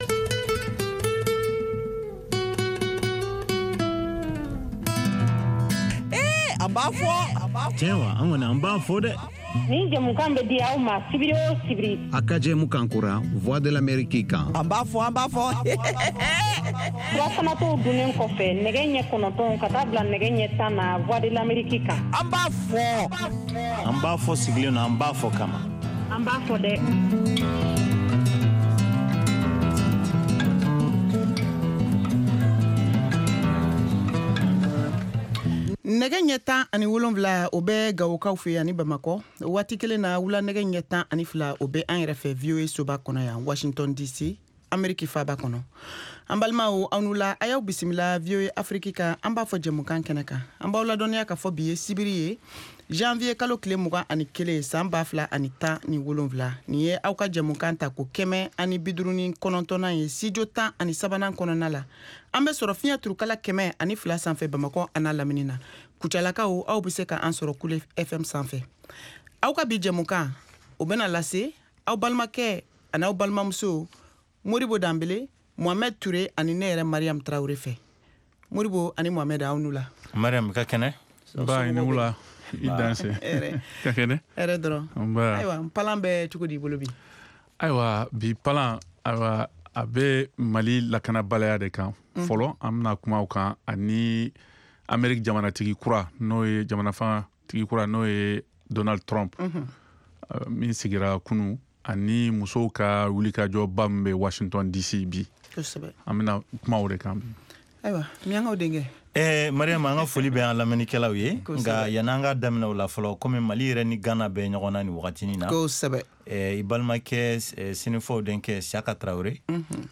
Bambara Call-in Show